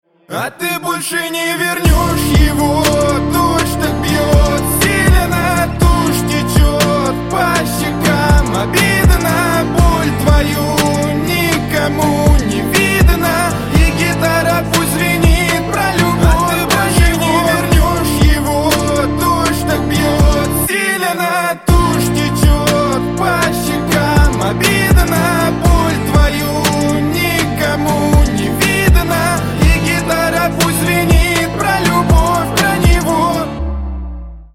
Грустные Рингтоны
Рэп Хип-Хоп Рингтоны